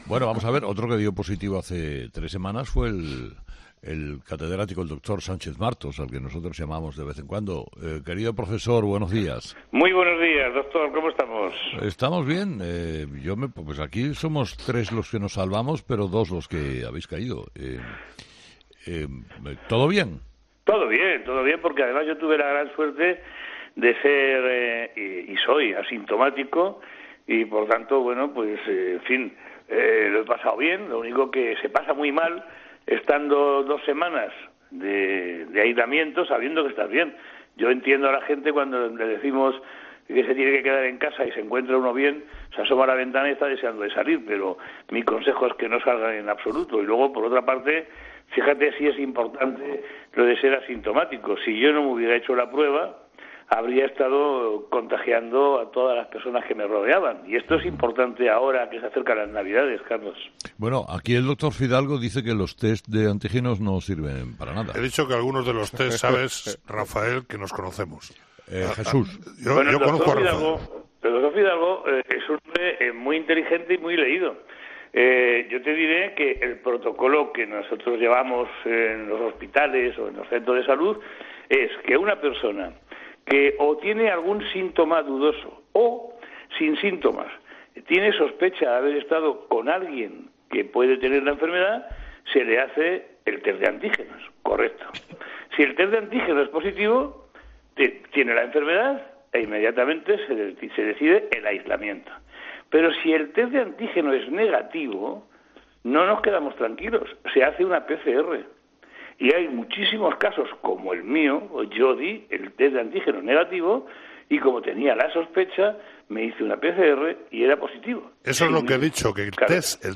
En una entrevista este jueves en "Herrera en COPE" , el médico ha asegurado que el test de antígenos no es suficiente.